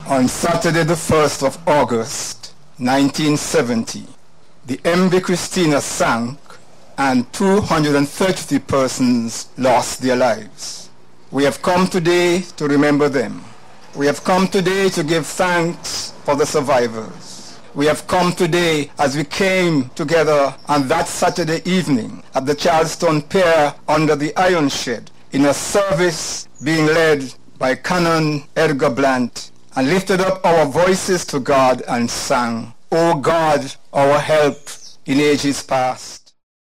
55th Memorial Service of the 1970 MV Christena Disaster held on Friday